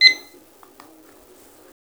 Alarm Sound Effect Free Download
Alarm